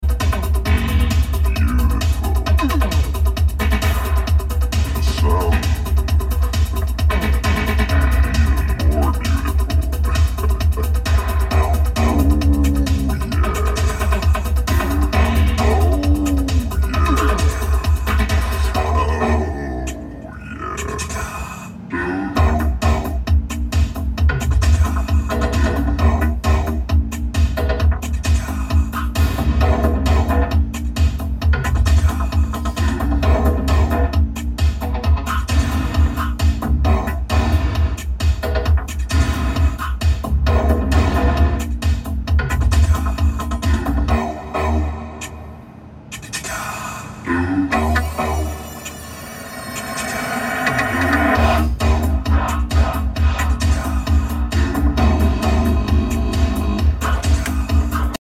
Audio with DSP after TUNING. sound effects free download